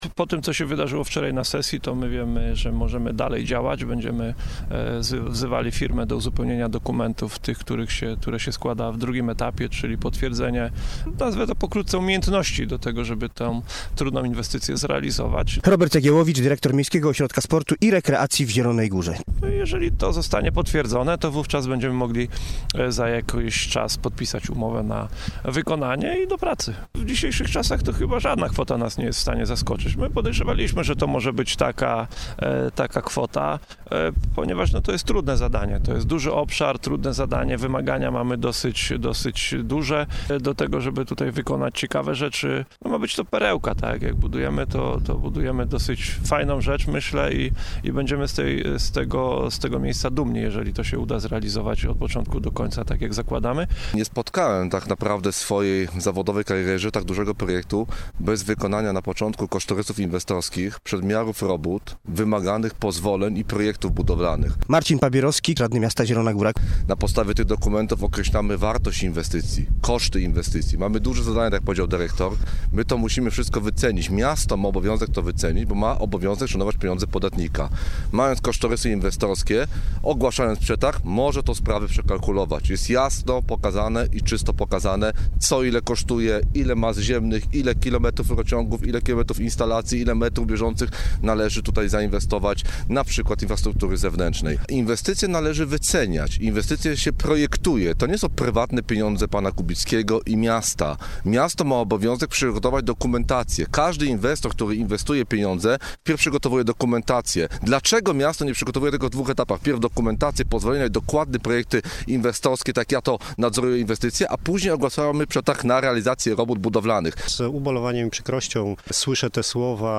Dziś w programie RZG Interwencje na te pytania próbowali odpowiedzieć: